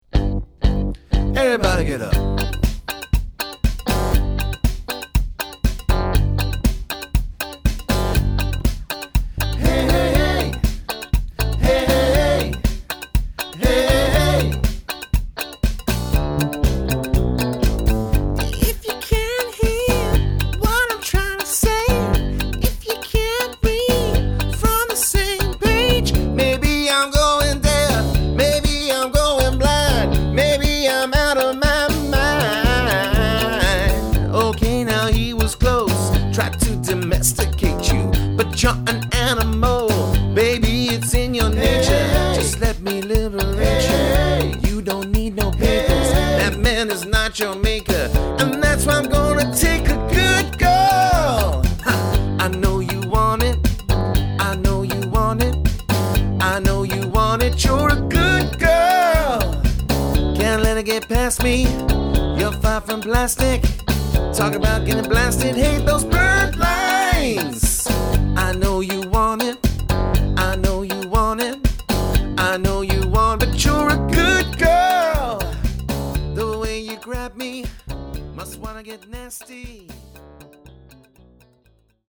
bursting with vibrance, fun and energy
rich, roaring voice
guitar
keyboard
bass
drums
• Highly experienced 4-7 piece function band